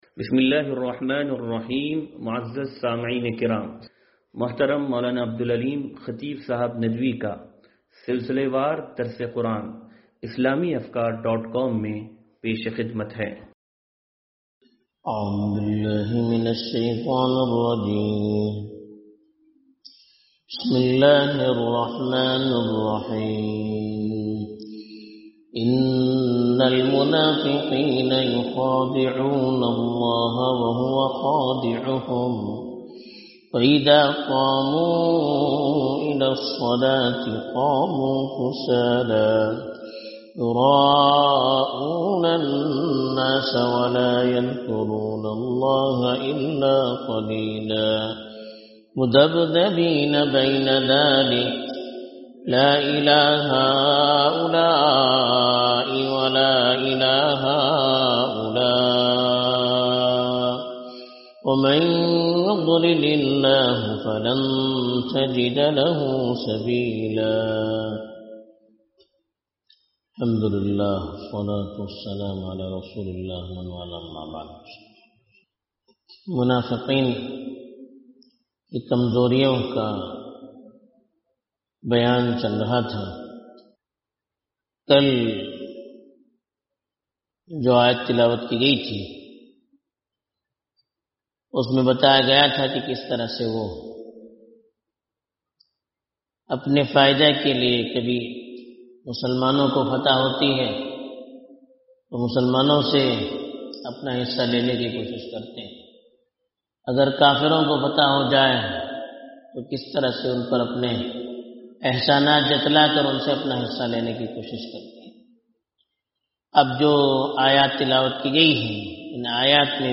درس قرآن نمبر 0408